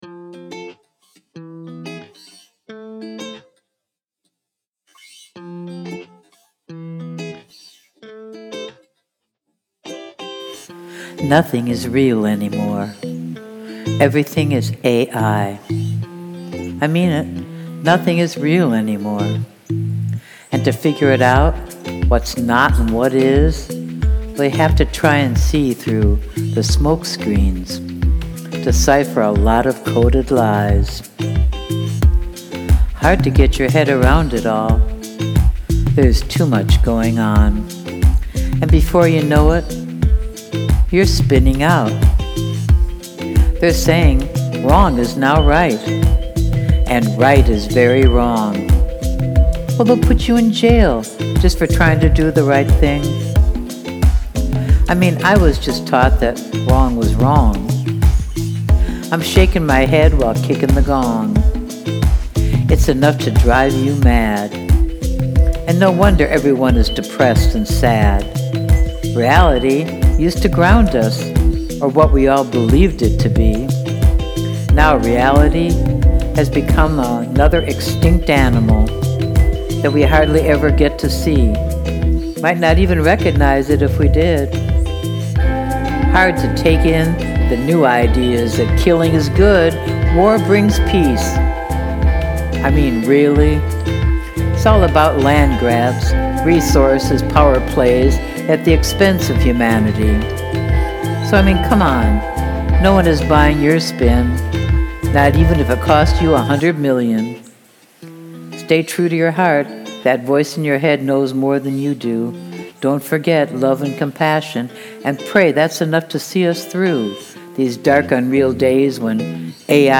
ai-rap.mp3